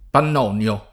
pann0nLo] etn. stor.; pl. m. ‑ni (raro, alla lat., -nii) — anche nome umanistico (sec. XV) del letterato Giano P. e del pittore Michele P. — come agg., spec. in certi sign., pannonico [